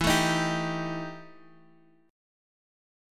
EmM7bb5 chord